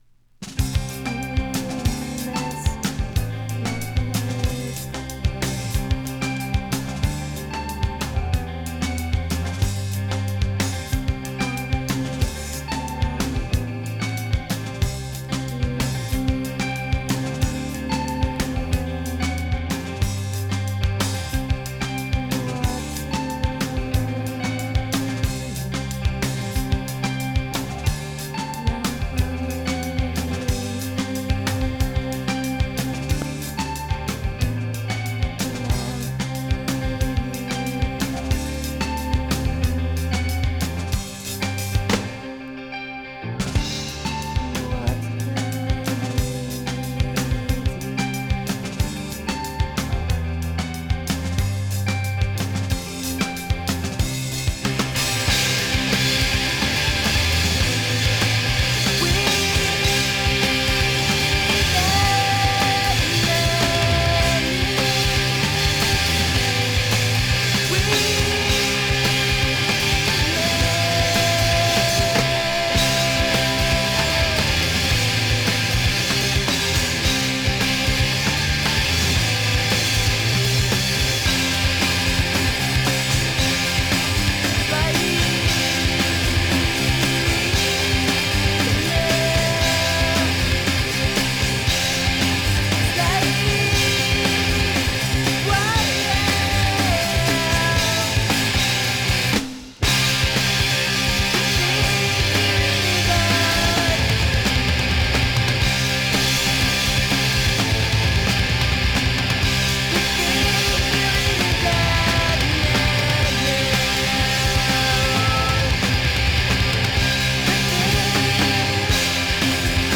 Vocals, Guitars
Bass
Drums
Emo